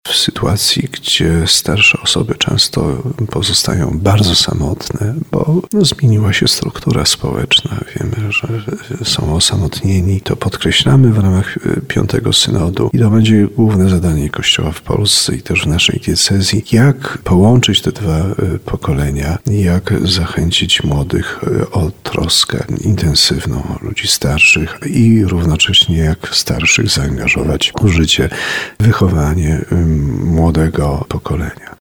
Dziś jest konieczność zbudowania pomostu między najmłodszym, a najstarszym pokoleniem – mówi Radiu RDN Małopolska biskup tarnowski Andrzej Jeż.